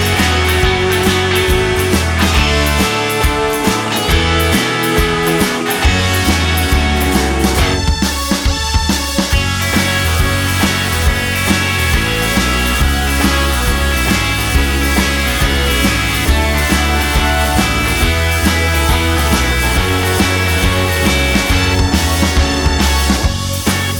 no Backing Vocals Indie / Alternative 3:59 Buy £1.50